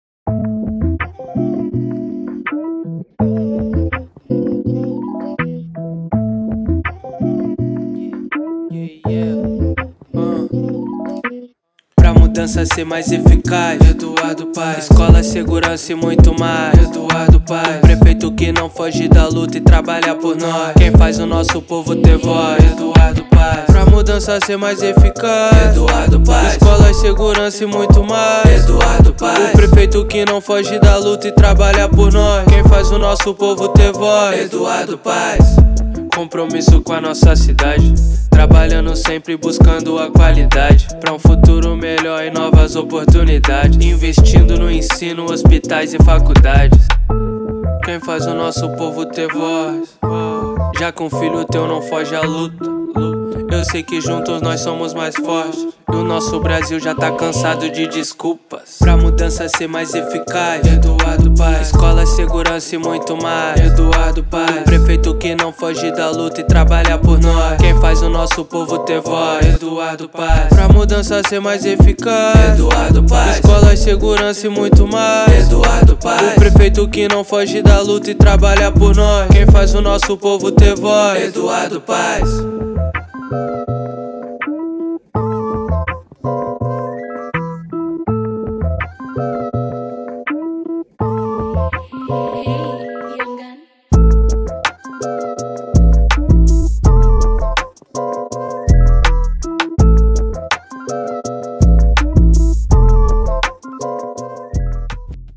jingle versão Rap